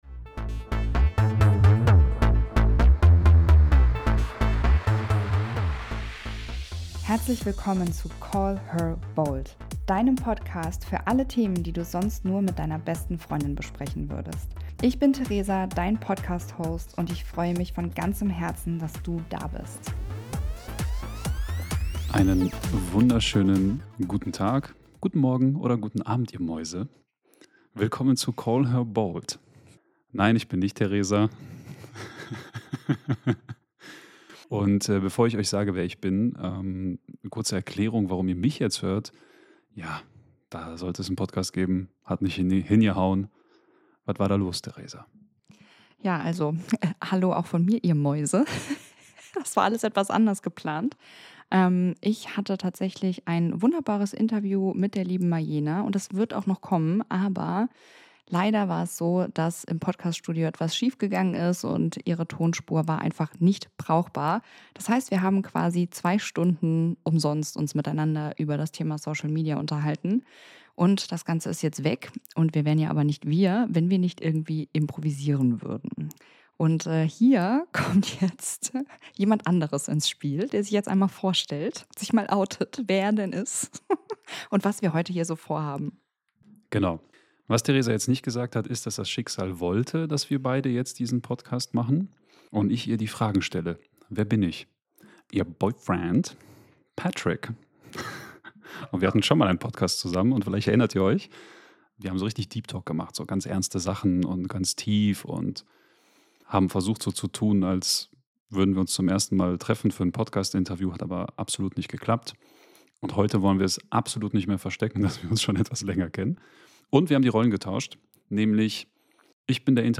In dieser besonderen Episode wird aus einer technischen Panne eine der ehrlichsten Podcastfolgen bisher. Kein Skript, keine Vorbereitung, kein doppelter Boden.